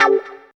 137 GTR 9 -L.wav